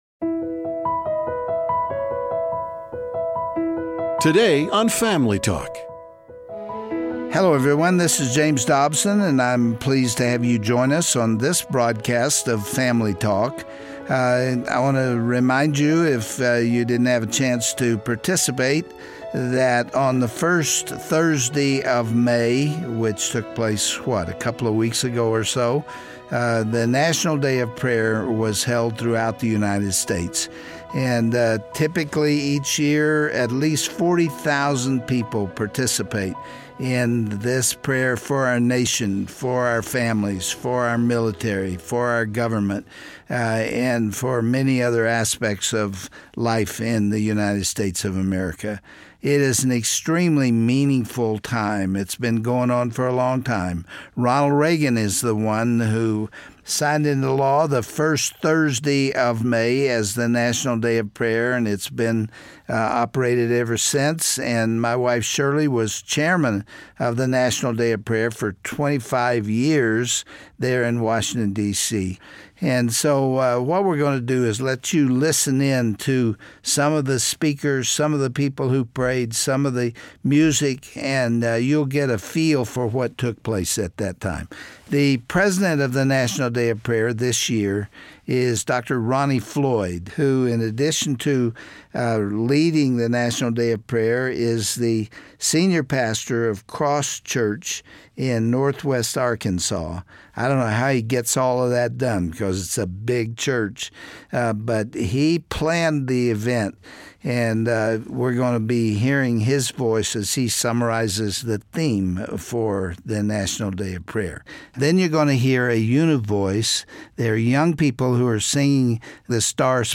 Dr. Dobson will reporton his trip to Washington, D.C. for the National Day of Prayer and share some of the most powerful prayers from this years event, calling for unity in the face of unrest and the necessity for Americas repentance.